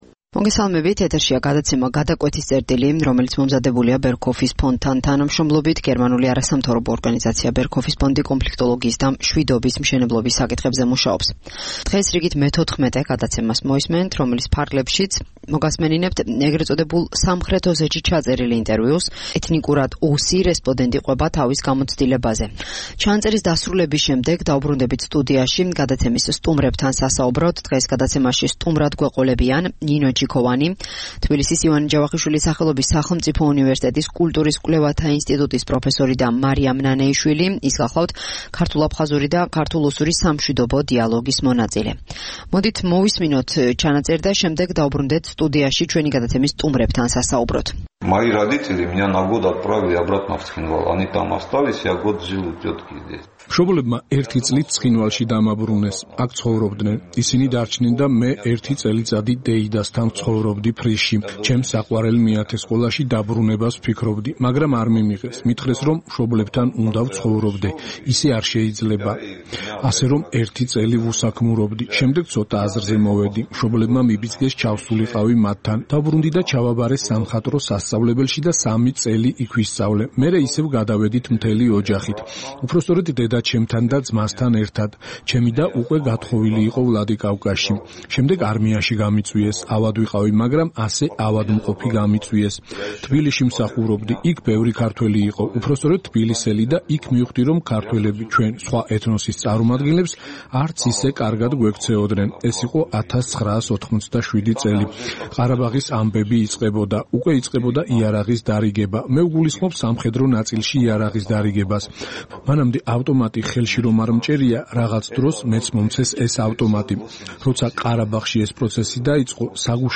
შემოგთავაზებთ ე.წ. სამხრეთ ოსეთში ჩაწერილ ინტერვიუს. რესპონდენტი ჰყვება საზოგადოებებს შორის დაწყებულ გაუცხოებაზე.